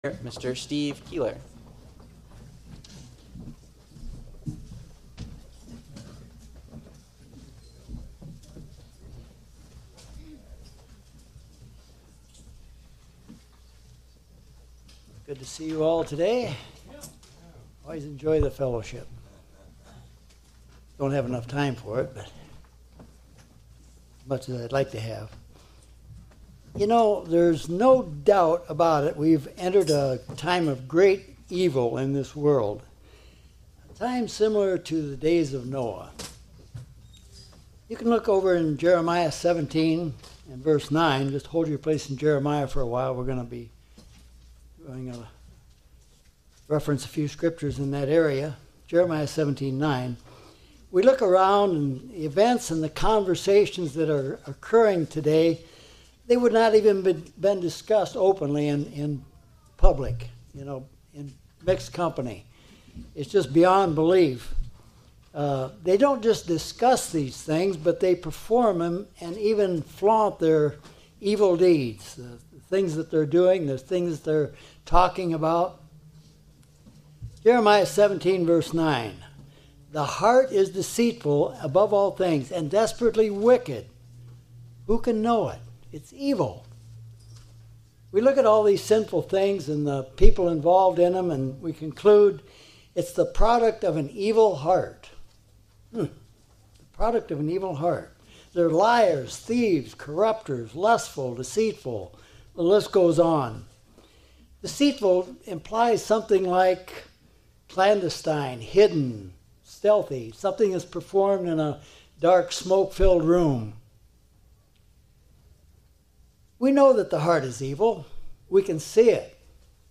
Sermons
Given in Tampa, FL